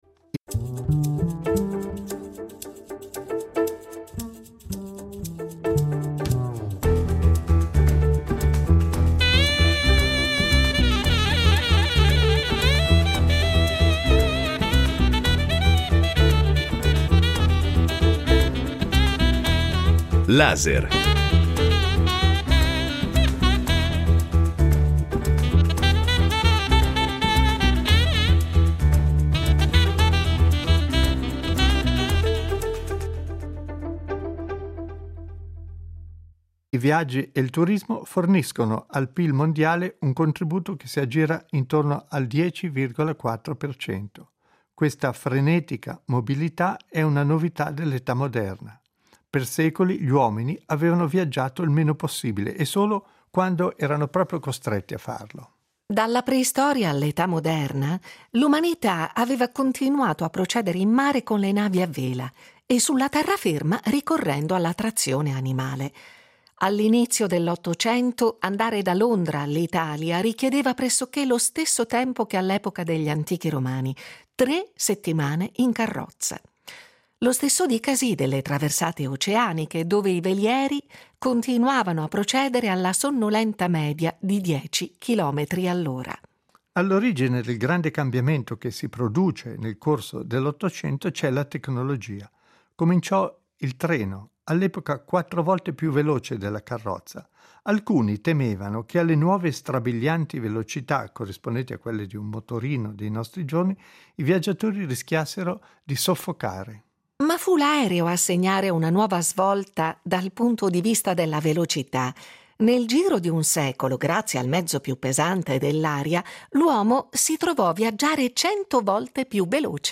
Nacquero architetture che non si erano mai viste prima nella storia dell’umanità, come le stazioni ferroviarie e gli aeroporti, che divennero le nuove cattedrali della Modernità, dove si celebrava il culto di due delle sue divinità più venerate: il dinamismo e la velocità. Delle conseguenze di questa svolta e delle prospettive future discutono un architetto, un antropologo e un geografo culturale.